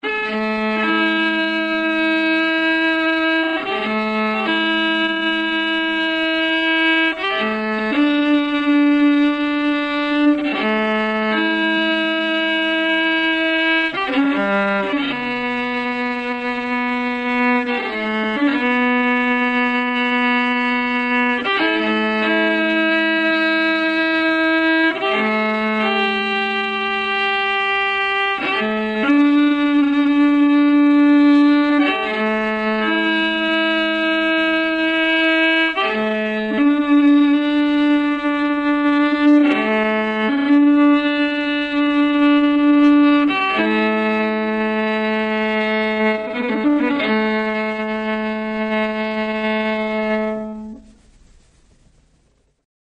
His close adherence to the sound and character of the bagpipe chanter and the absence of a full tone or expression through dynamics, vibrato or bow articulation places his style well outside both the classical violin tradition and that dominant strand in Scottish fiddling that relies heavily upon it.
In this extract of the final section, the audio has been tuned to A=440, slowed down and dropped by one octave to allow the relationship between melody and ornamentation to be heard better:
lament-for-rory-a440-low-end.mp3